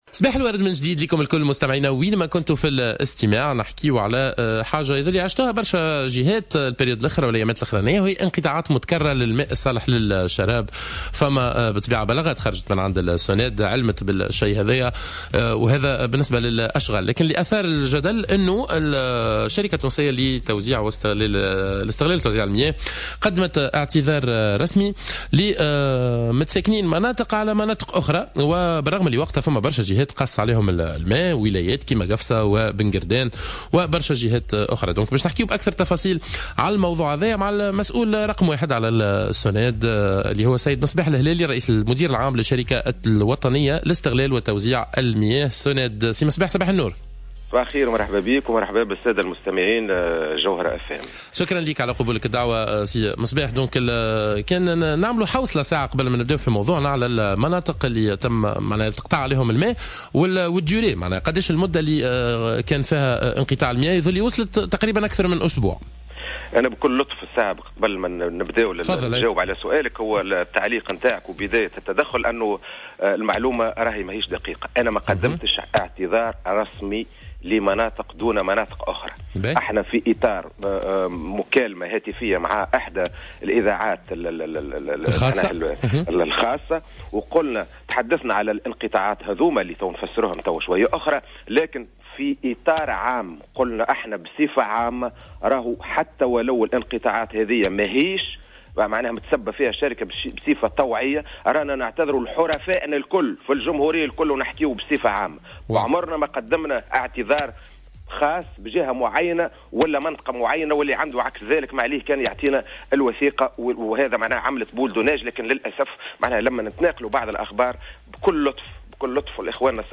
وأوضح في اتصال مع برنامج "صباح الورد" ان الاعتذار جاء في سياق تصريح اذاعي وفي رد على سؤال حول انقطاع المياه بمناطق في تونس العاصمة مؤخرا، وأن الاعتذار جاء في اطار عام بشكل عام وموجه للحرفاء في كامل انحاء الجمهورية بسبب اشغال الصيانة التي تقوم بها الشركة بعدد من المناطق والتي تؤدي عادة الى انقطاع المياه. وأكد قيام الشركة بأشغال صيانة في هذه الفترة تفاديا لفترة ذروة الاستهلاك في الصيف.